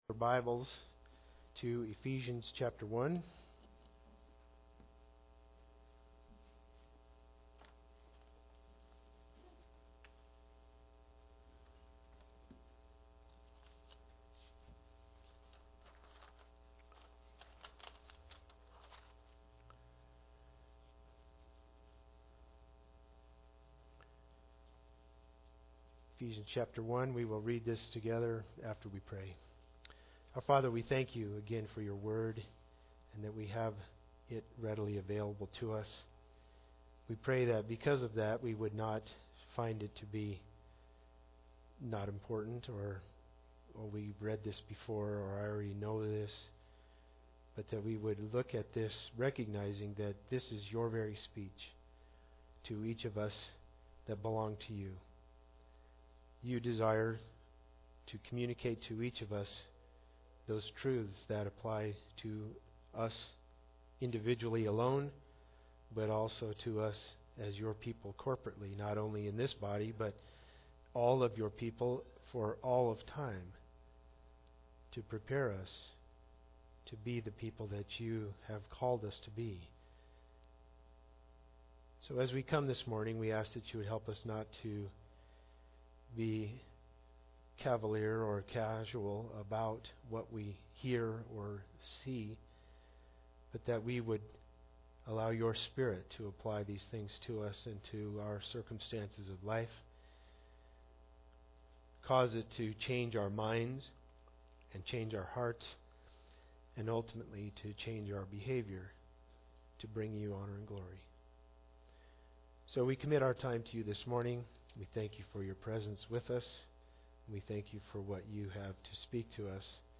Ephesians 1:1-23 Service Type: Sunday Service Bible Text